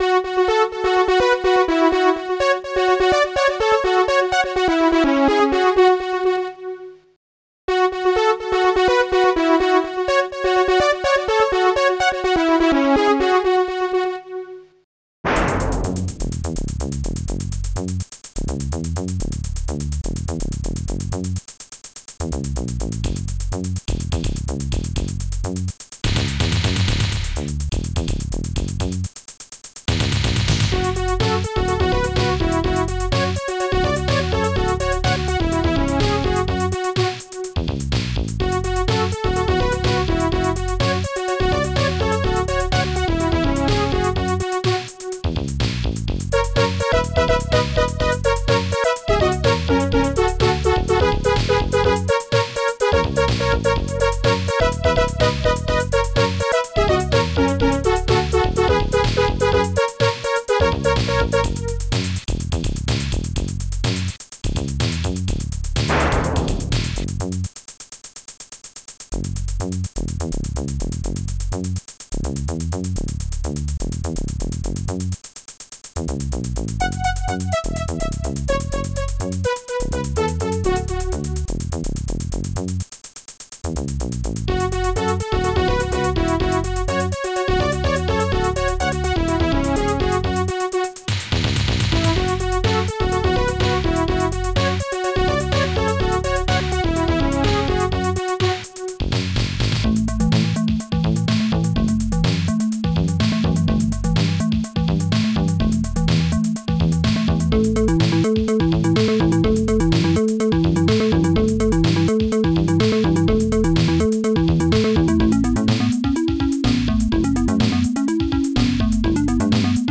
Type Amos Music Bank Tracker